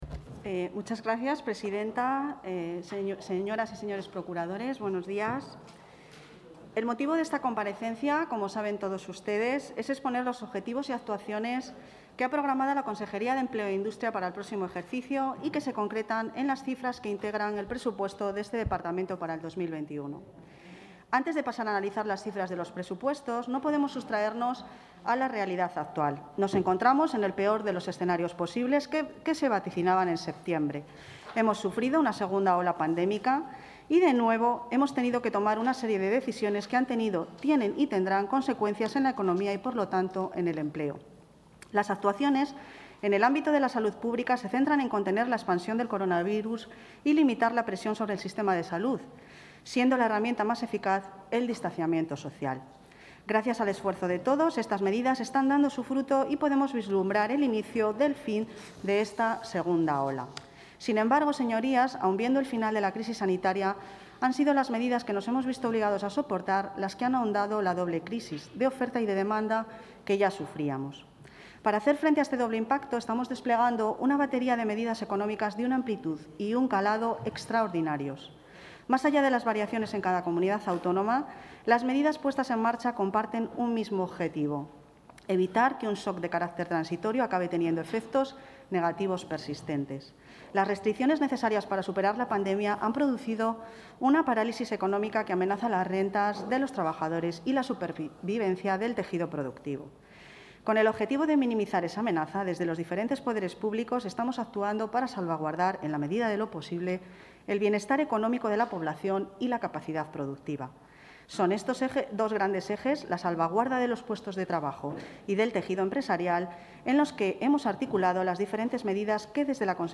La consejera de Empleo e Industria, Ana Carlota Amigo, comparece esta mañana en la comisión de Economía y Hacienda de las Cortes...
Intervención de la consejera de Empleo e Industria.